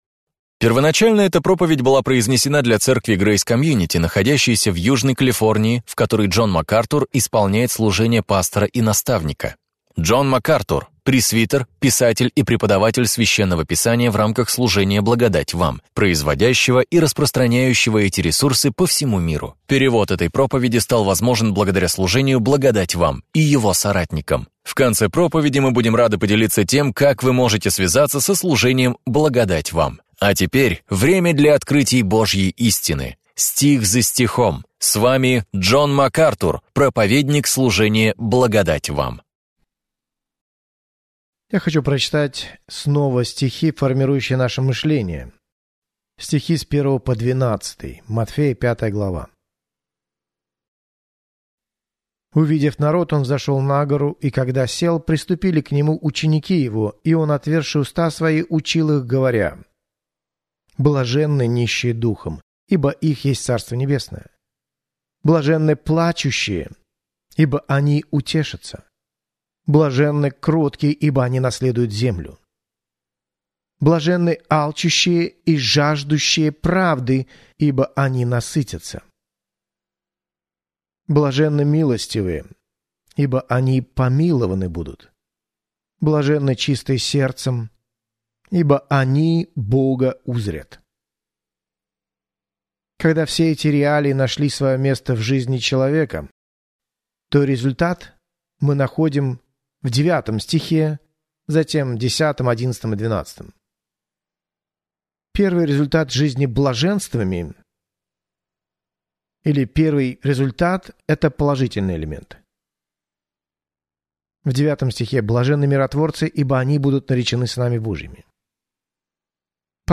В своей проповеди «Заповеди блаженства» Джон Макартур делает обзор утверждений Христа – заповедей блаженства, исследующих моральные, этические и духовные предписания, которыми руководствуются Божьи люди.